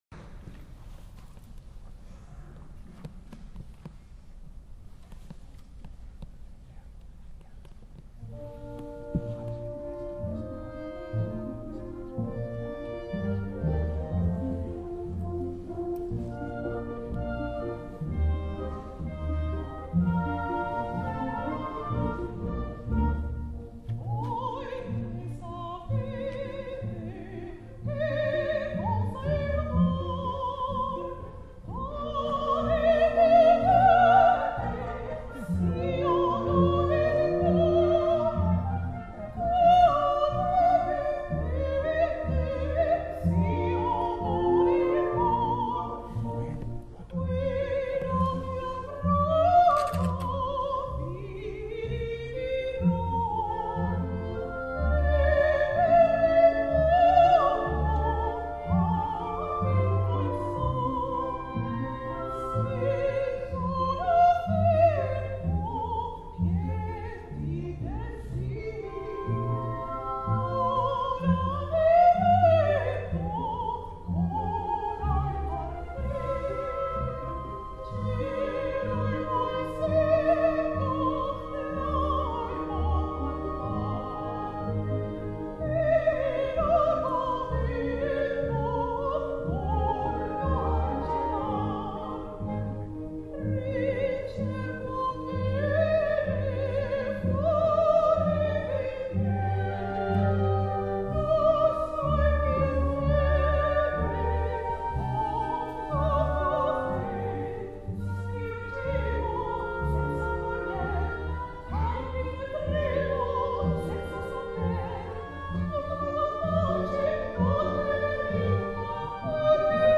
Voi Che Sapete from Le Nozze Di Figaro by Mozart with the Alhambra Orchestra